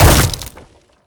wood_hit1_hl2.ogg